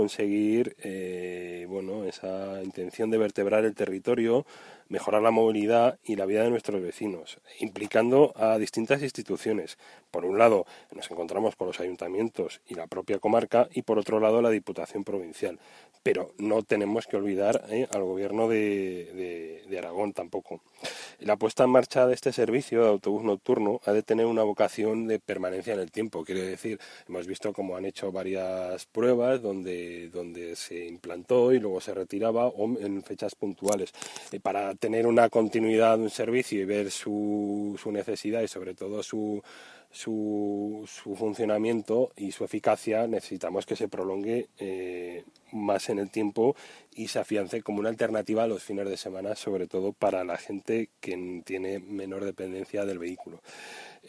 Audios de Enrique Novella, portavoz de C’s en la Comarca La Hoya de Huesca: